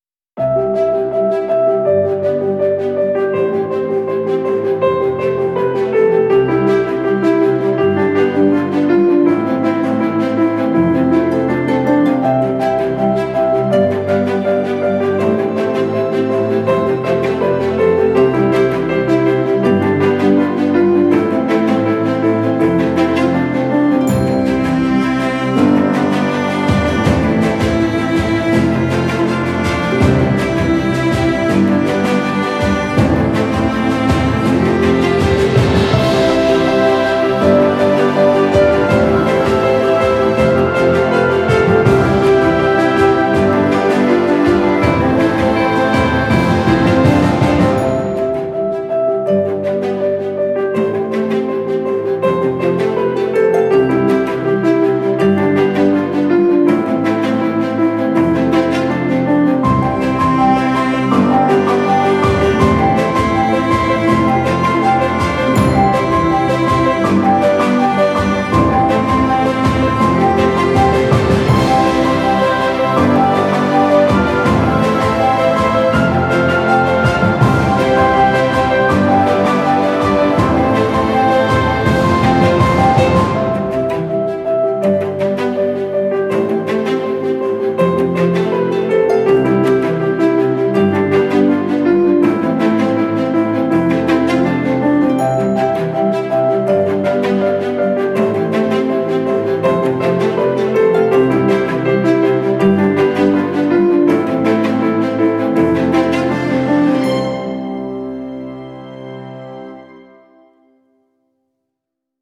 Genre: filmscore, classical.